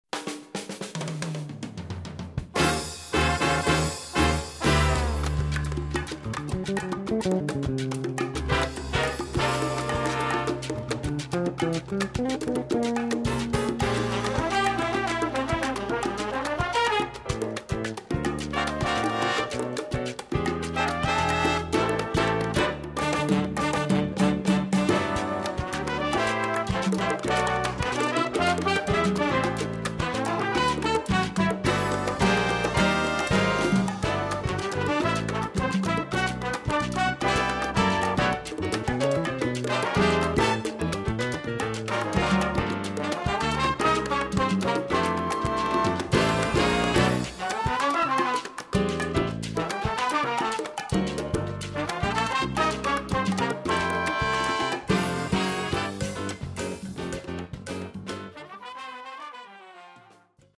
Latin jazz
Category: little big band
Style: mambo
Solos: tenor sax/piano